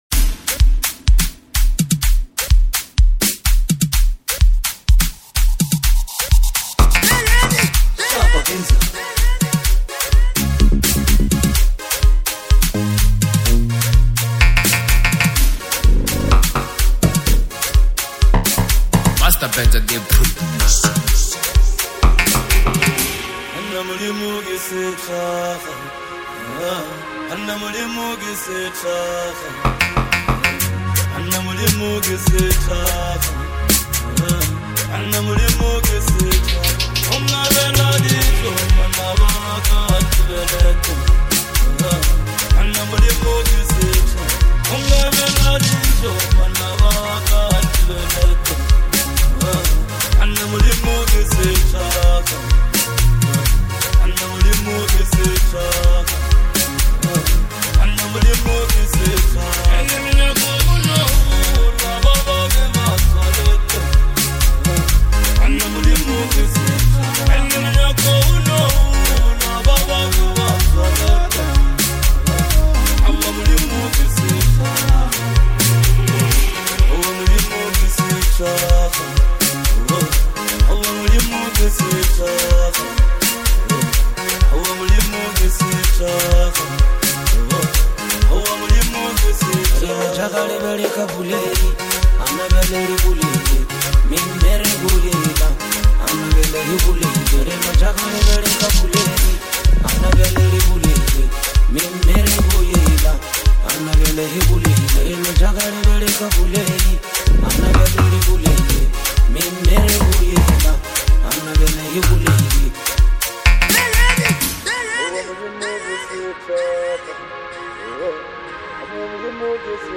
Lekompo
Genre: Lekompo.